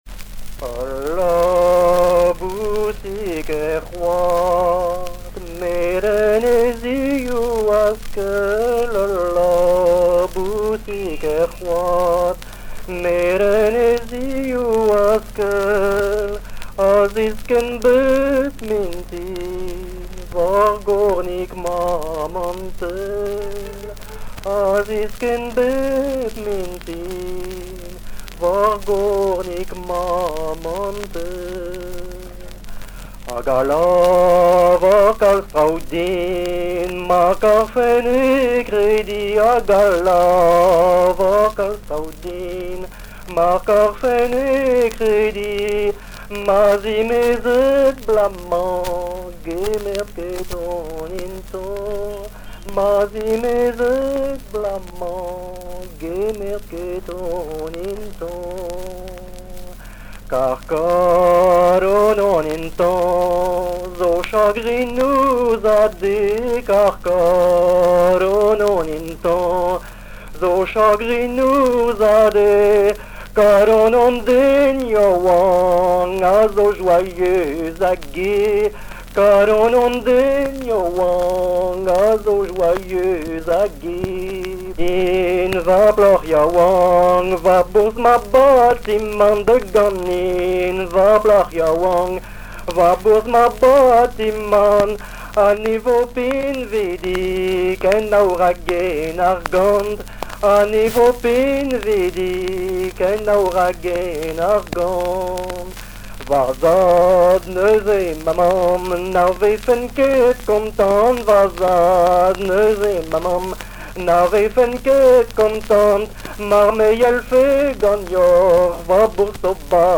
Genre strophique
Chansons et témoignages
Catégorie Pièce musicale inédite